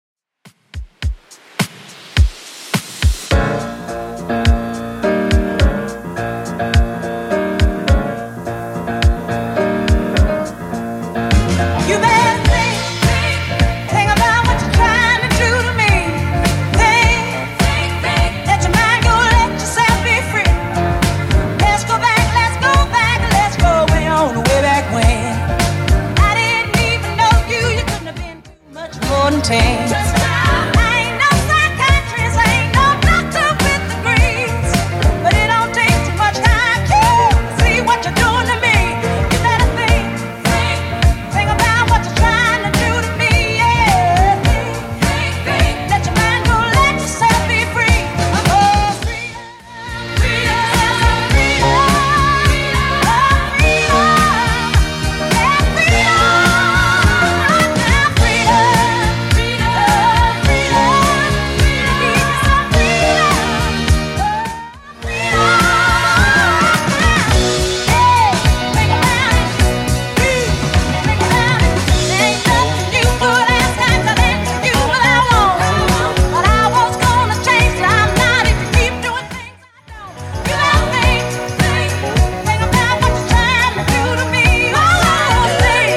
Genre: 80's Version: Clean BPM: 118